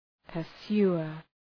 {pər’su:ər}